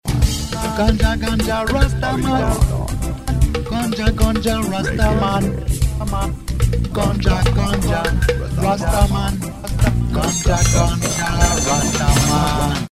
Reggae - Ragga